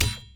sword1.wav